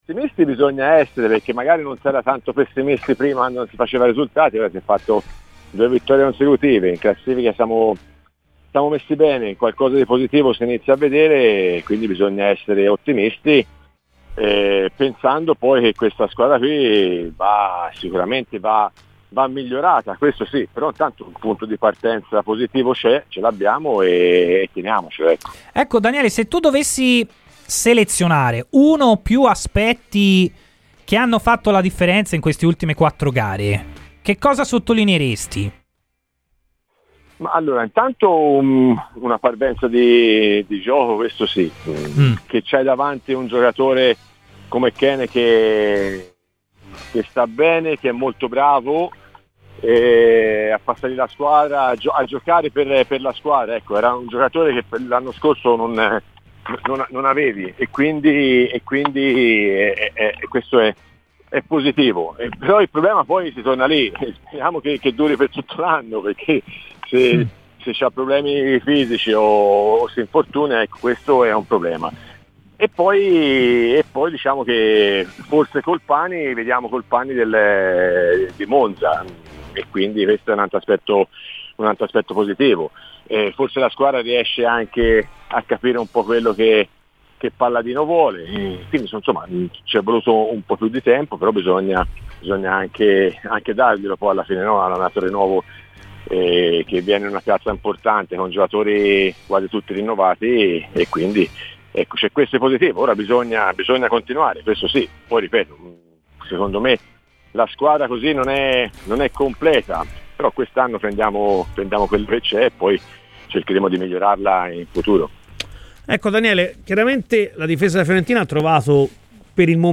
Daniele Carnasciali, ex difensore viola, è intervenuto a Radio FirenzeViola per parlare del momento della Fiorentina: "Vedo una parvenza di gioco.